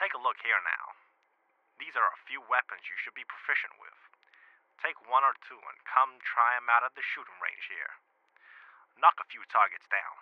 take a look here now, these are a few weapons you need to be more proficient with.wav